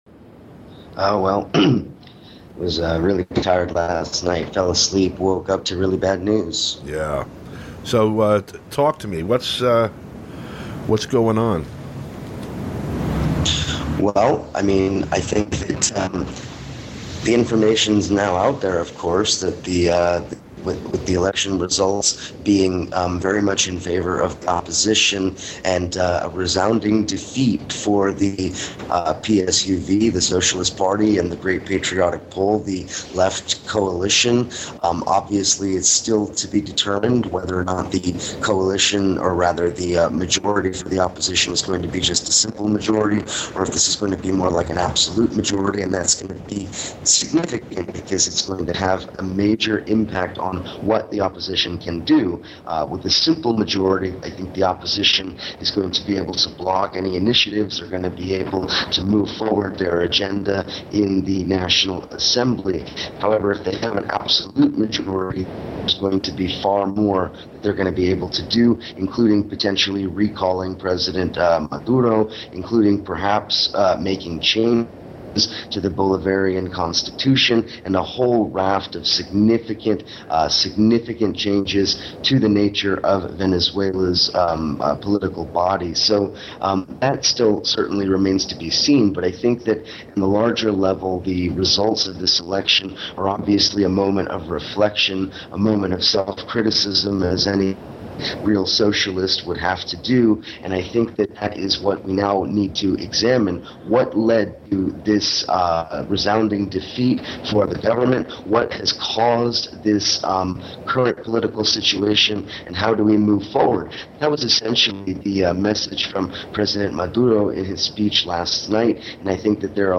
Program Type: Interview Speakers